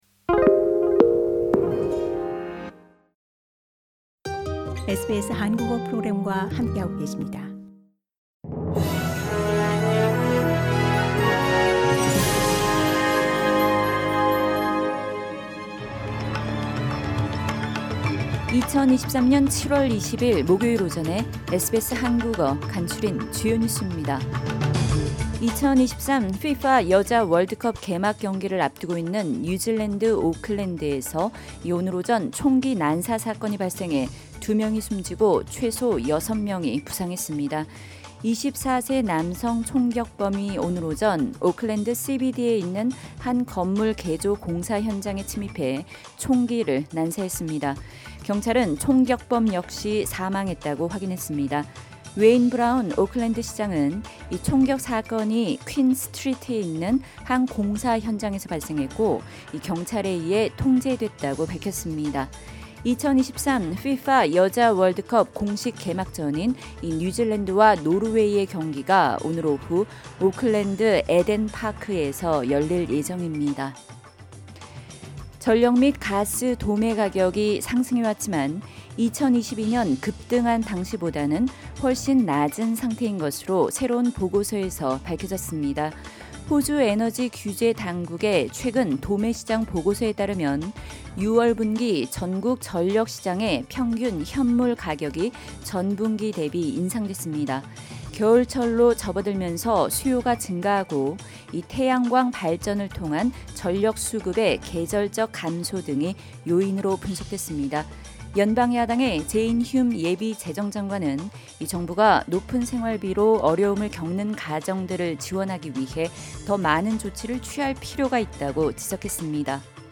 SBS 한국어 아침 뉴스: 2023년 7월20일 목요일